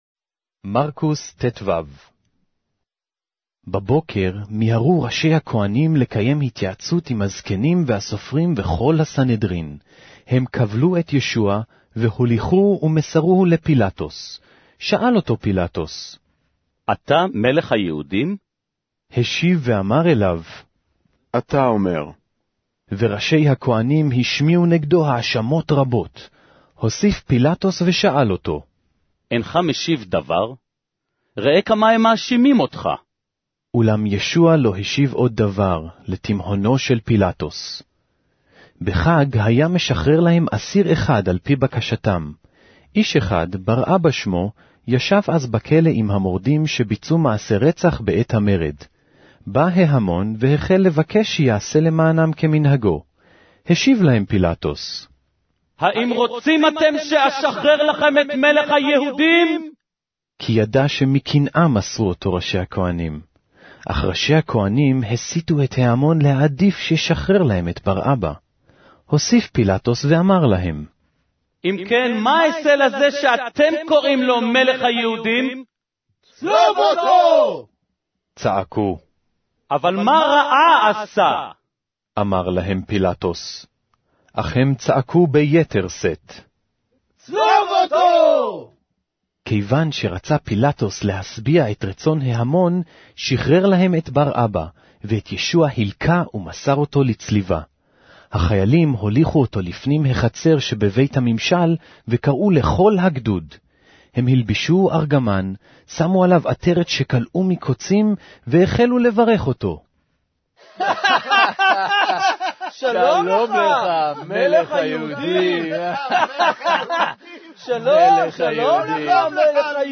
Hebrew Audio Bible - Mark 7 in Web bible version